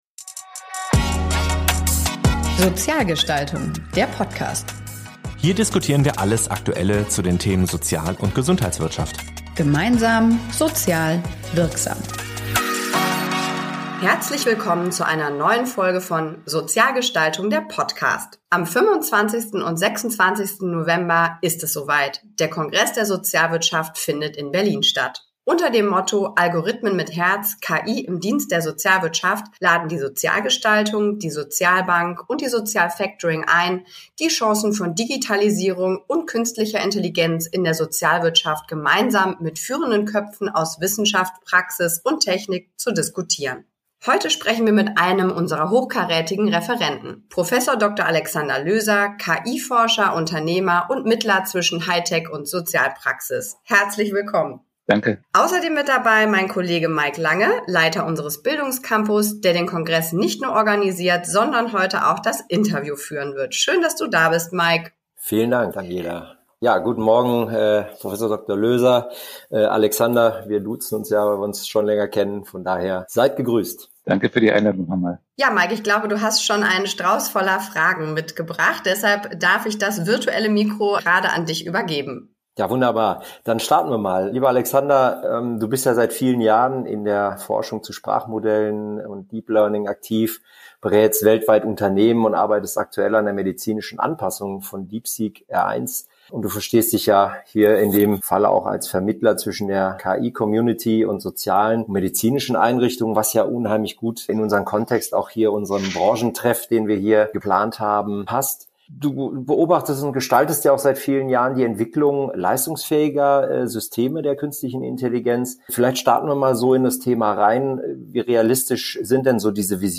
interviewt den renommierten KI-Forscher und Unternehmer